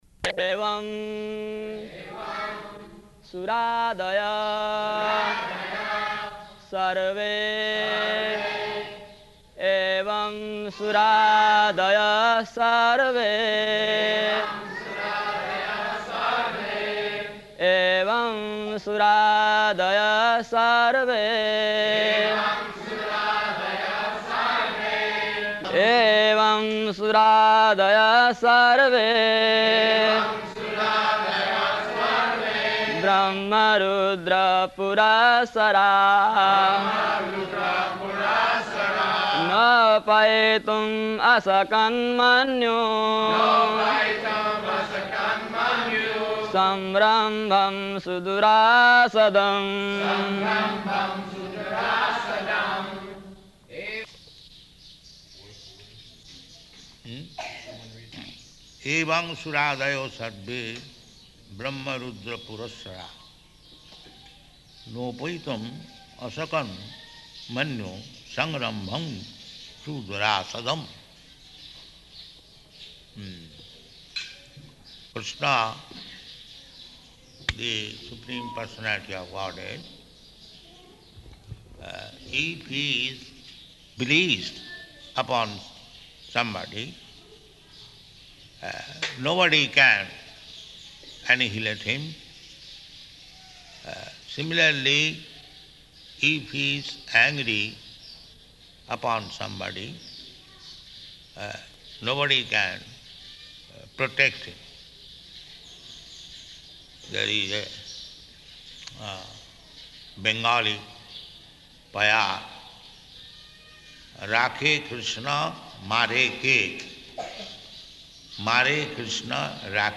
Location: Māyāpur
[chants verse; Prabhupāda and devotees respond]